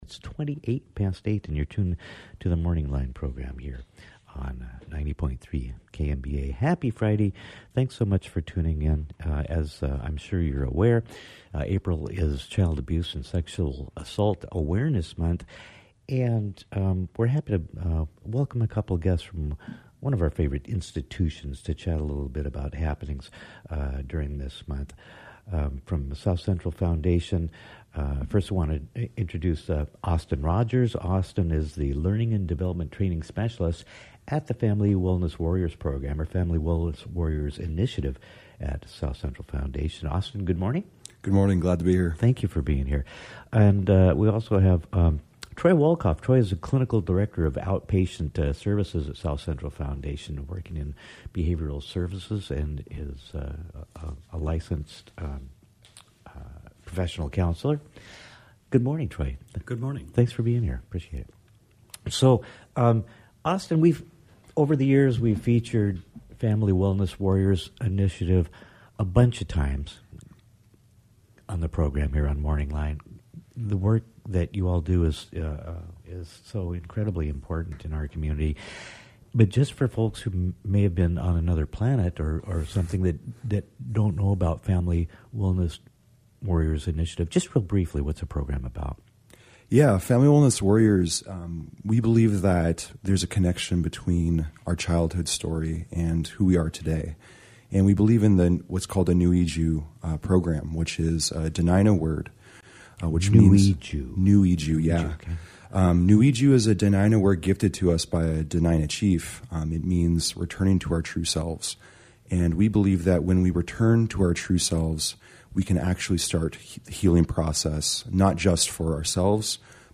Southcentral Foundation Family Wellness Warriors and Behavioral Services Division discussed Child Abuse and Sexual Assault Awareness Month on KNBA 90.3. Topics included returning to our true selves through community and generational healing.